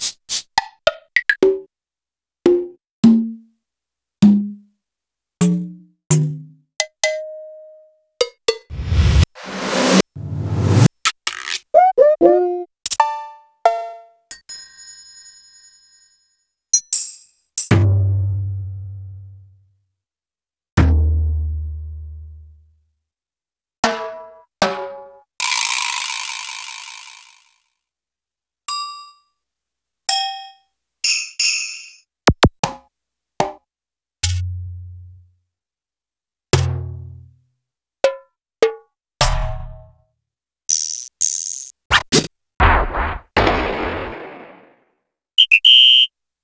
Roland_R-70_Perc.wav